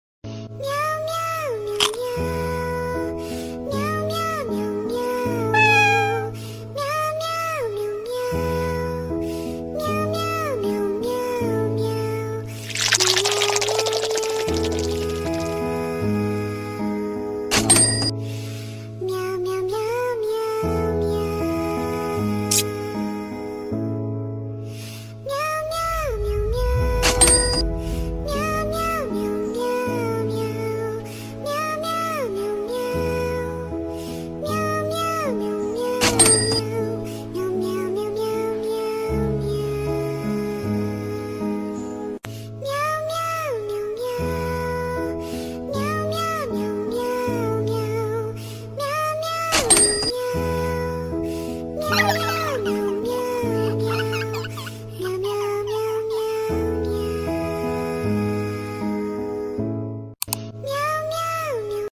Meow meow meow meow orignalsound#cat#car#kitten#meow sound effects free download